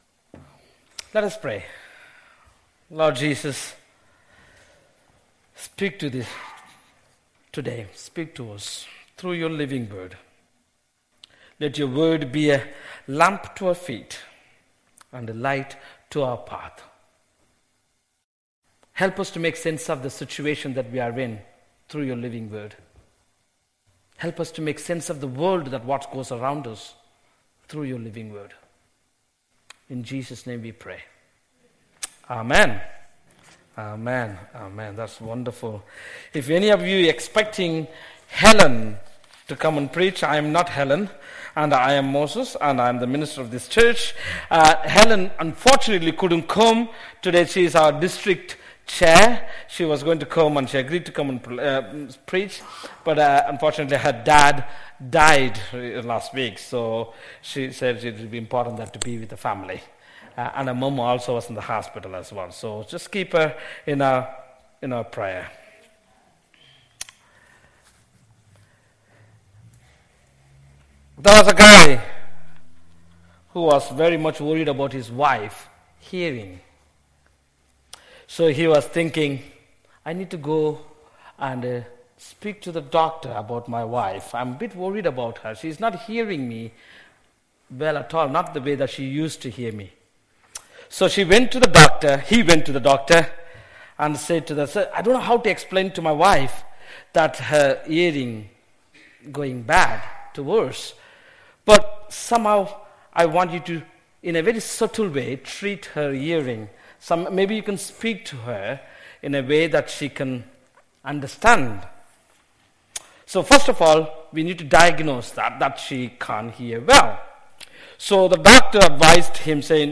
There is an audio version of the sermon also available.
05-11-sermon.mp3